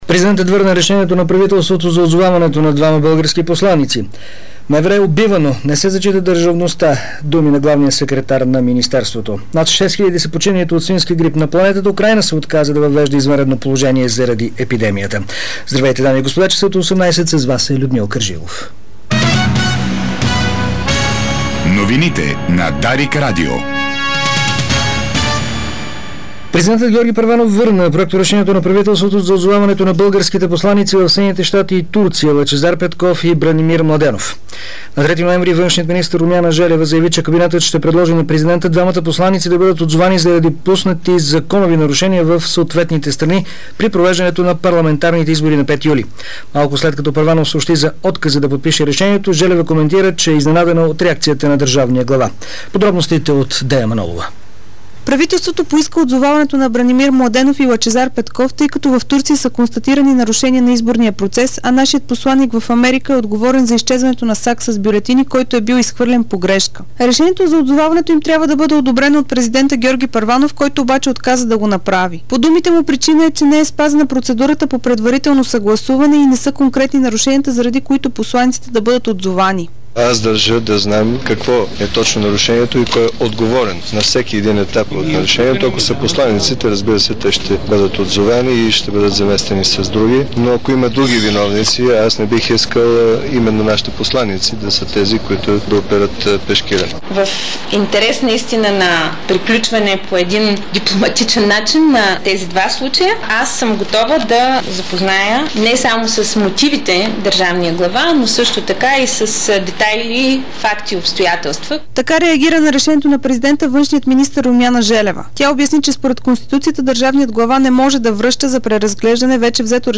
Обзорна информационна емисия - 07.11.2009